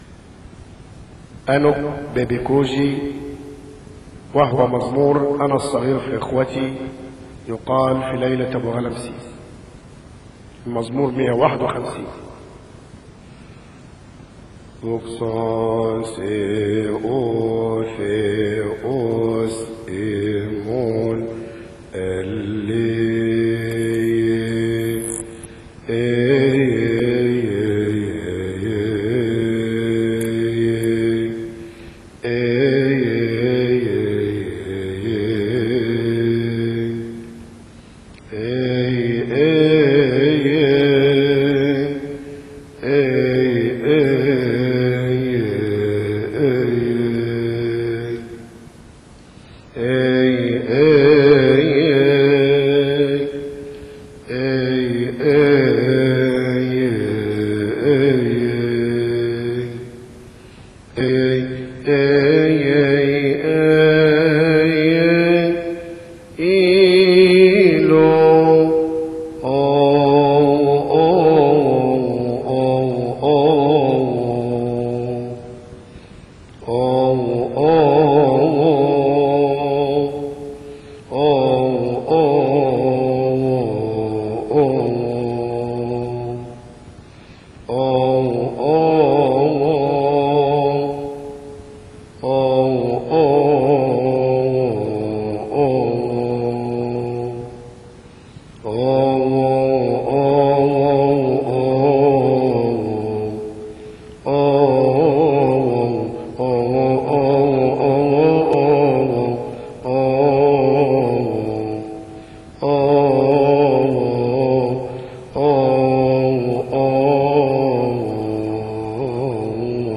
لحن: المزمور 151